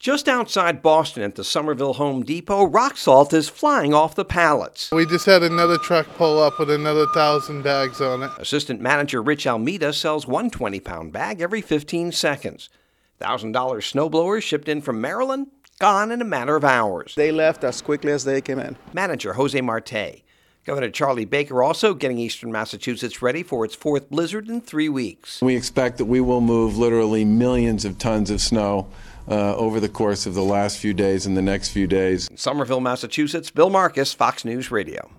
HAS MORE FROM A HOME DEPOT OUTSIDE BOSTON.